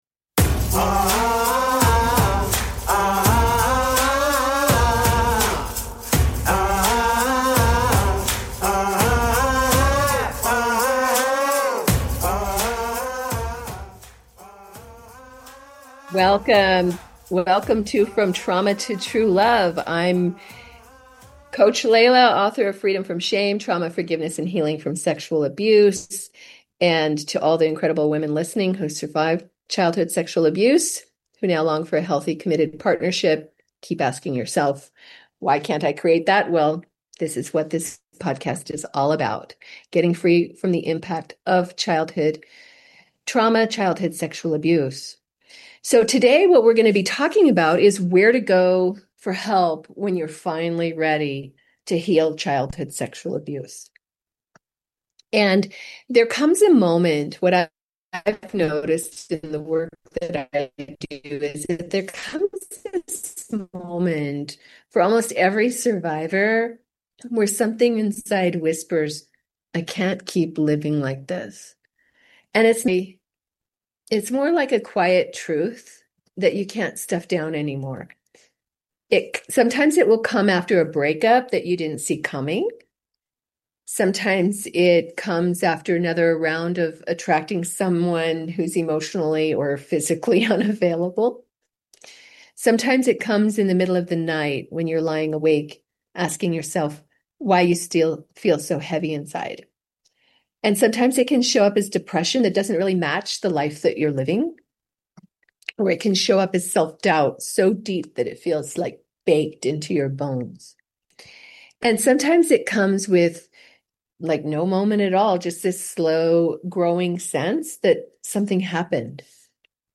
Talk Show Episode, Audio Podcast, From Trauma To True Love and S2E10, Starting Your Healing Journey from CSA on , show guests , about Starting Your Healing Journey from CSA, categorized as Health & Lifestyle,Love & Relationships,Relationship Counseling,Psychology,Emotional Health and Freedom,Mental Health,Personal Development,Self Help,Society and Culture